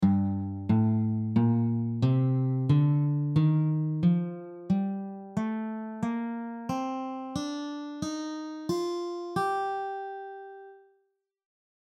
Each scale below will cover two octaves on the guitar in standard notation with tabs and audio examples included.
G minor scale
The notes of the G natural minor scale are G, A, Bb, C, D, Eb, and F.
G-minor-Gm-scale-audio.mp3